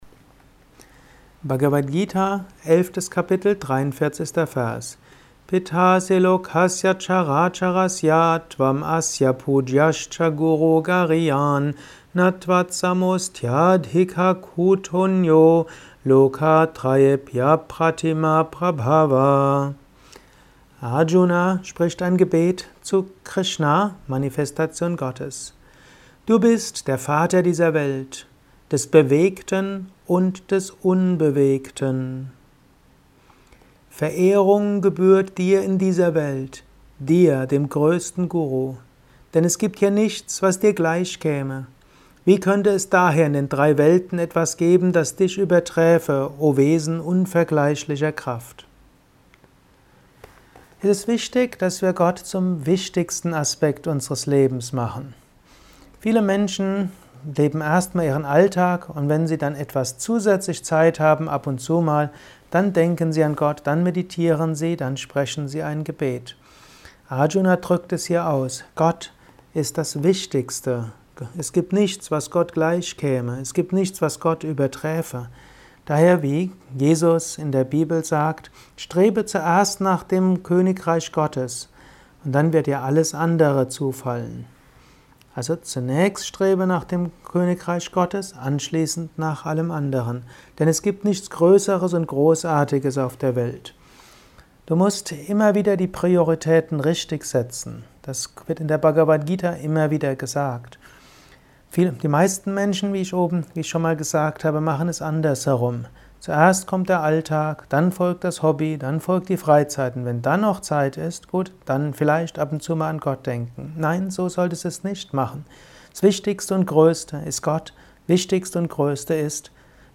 Dies ist ein kurzer Kommentar als Inspiration